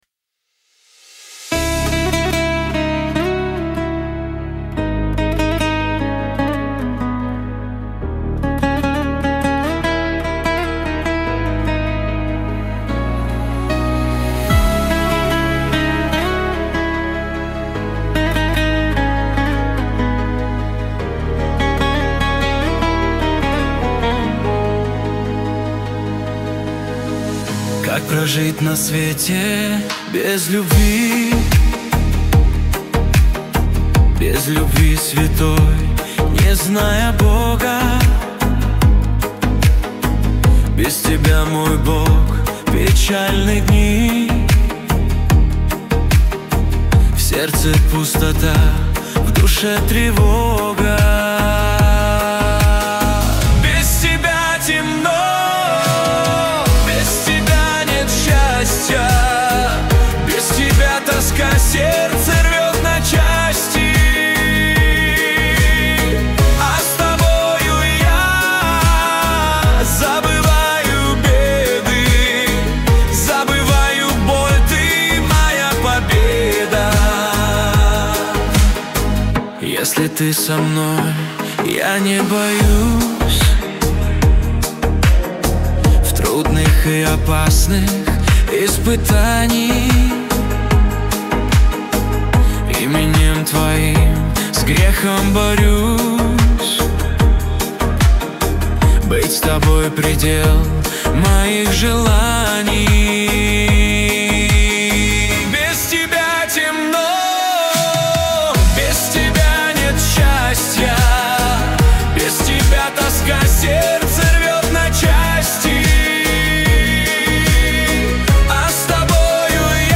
песня ai
1085 просмотров 1977 прослушиваний 464 скачивания BPM: 149